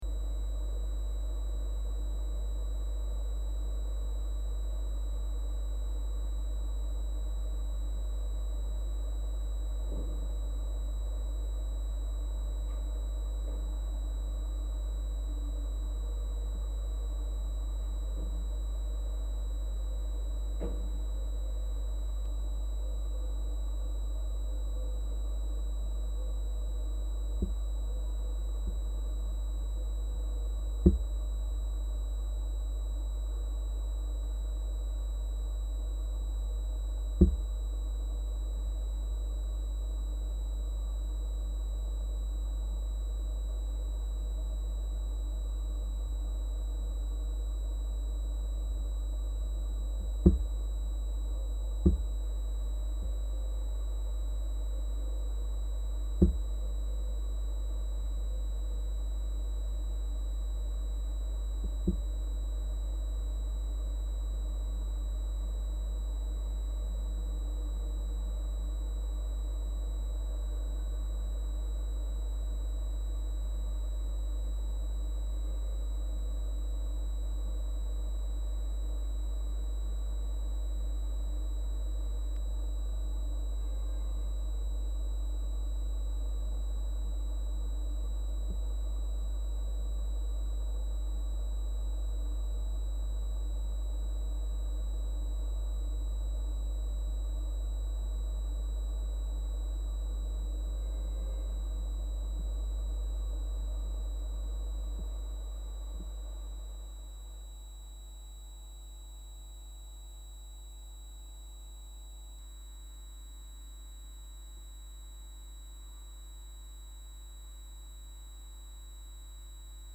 installation (with sound); 2010
“The Third Party” group exhibition; Platform China
recorded sound (1 hour & 14 seconds, loop, inside the hall)
outdoor environment sound and noise of electricity (inside the cabin)